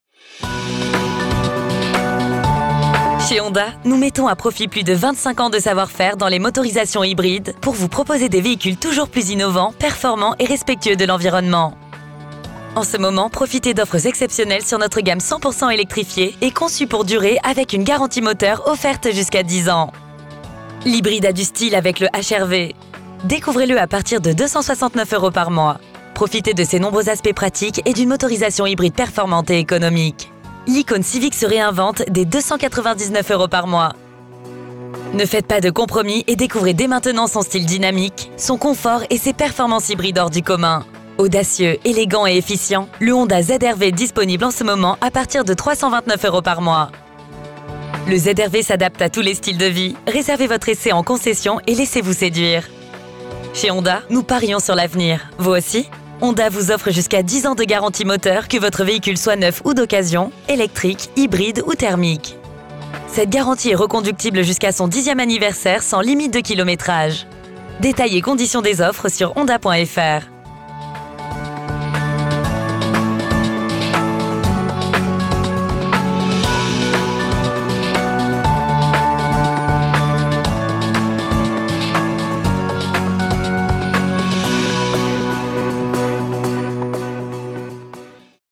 Accueil téléphonique
L’enregistrement de vos messages est réalisé par des professionnels dans notre studio d’enregistrement.